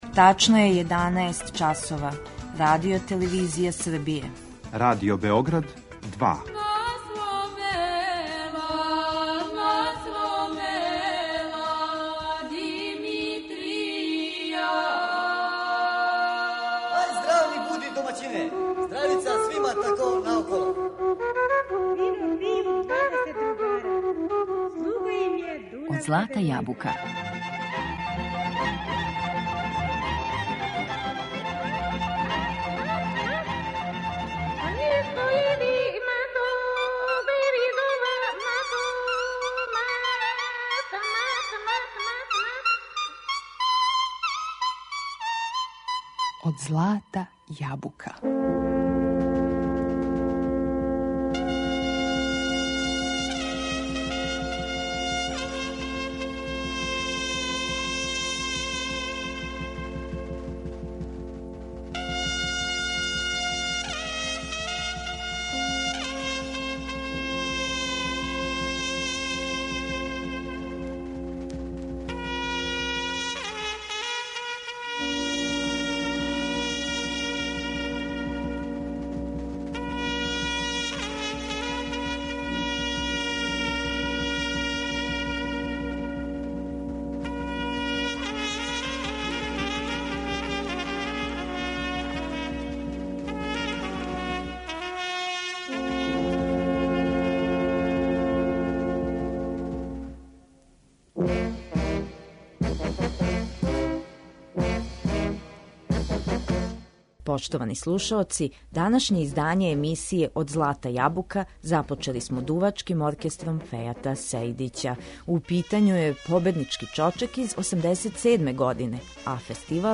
Слушаћемо кола и песме који су симболи одређеног краја у Србији.